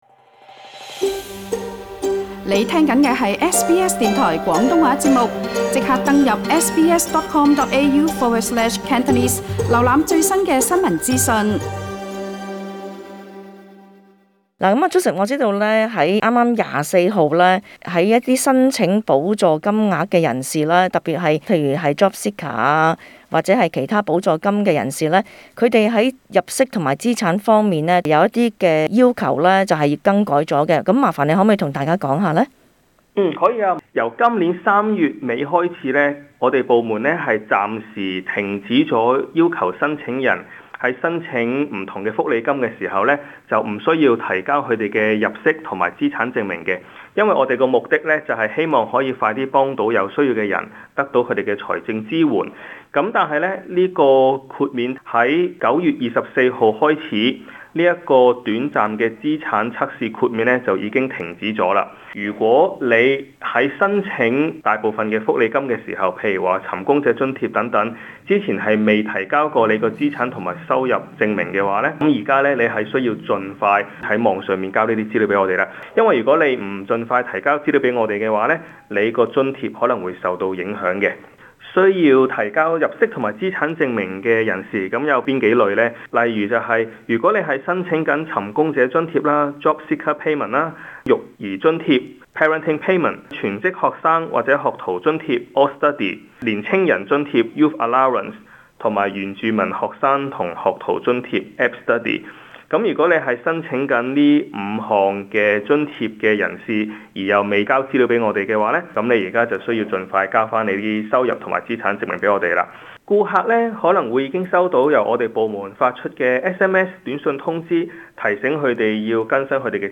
【社區專訪】keep FIT 定係 keep FAT 完全由你揸曬FIT